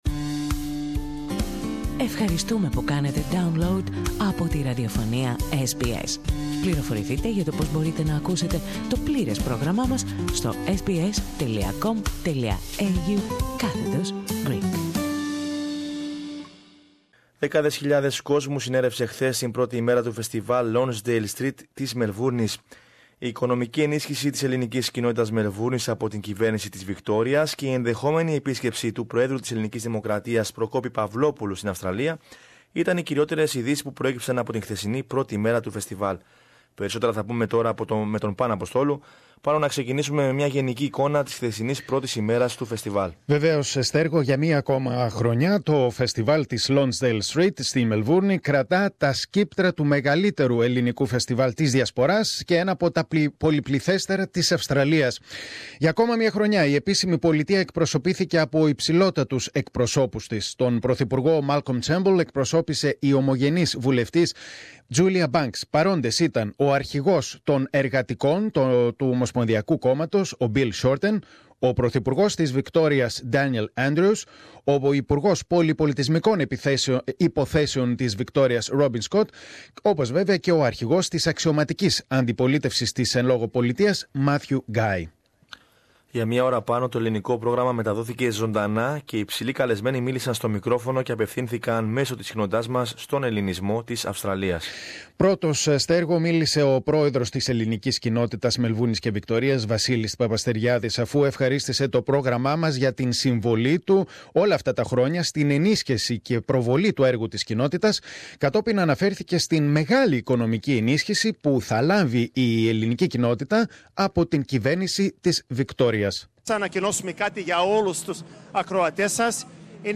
Με απόλυτη επιτυχία στέφθηκε η πρώτη μέρα του Φεστιβάλ της Lonsdale Street και το Ελληνικό Πρόγραμμα μεταδόθηκε απευθείας από την καρδιά του Φεστιβάλ.
Για μία ώρα, το Ελληνικό Πρόγραμμα μεταδόθηκε ζωντανά και υψηλοί καλεσμένοι μίλησαν στο μικρόφωνο και απευθύνθηκαν μέσω της συχνότητάς μας στον Ελληνισμό της Αυστραλίας.